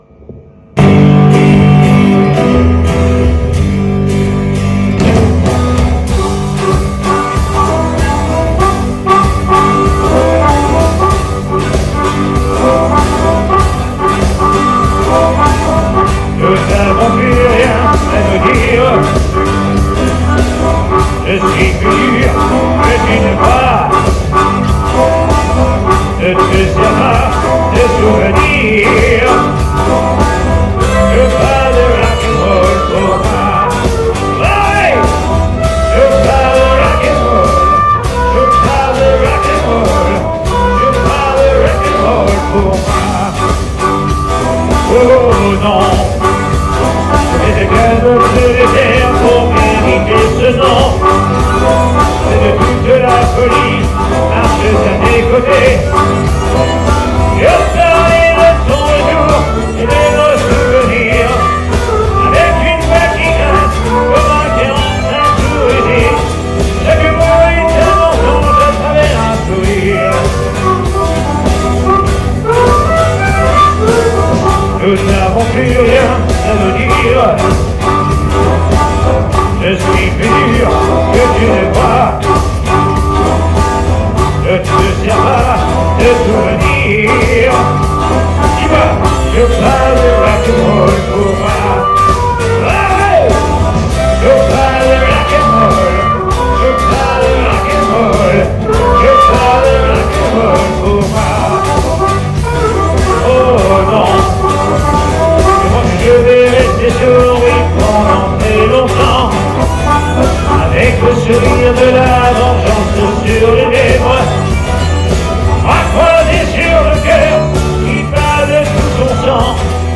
SOIREES BLUES-ROCK RETROSPECTIVE
DUO CHANT/HARMONICA
soiree bistrot d'oliv - 14-12-19